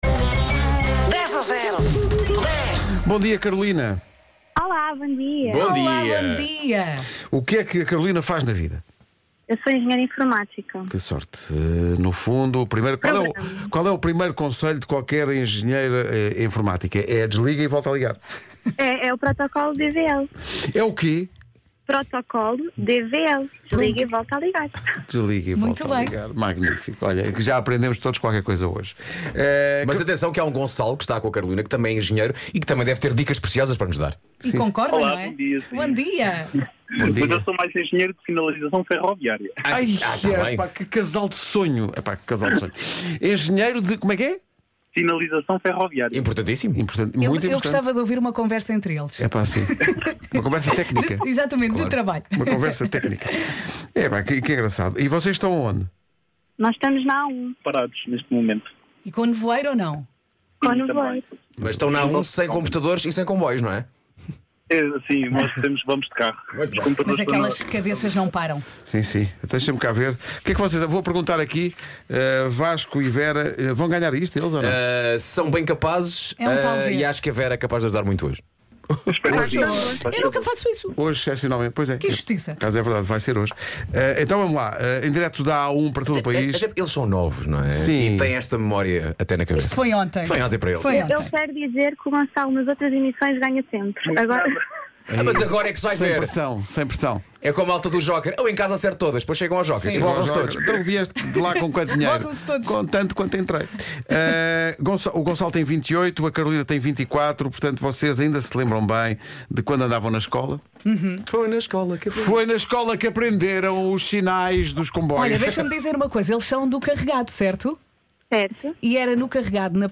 DEJAJERO ESPECIAL: PEDIDO DE CASAMENTO EM DIRETO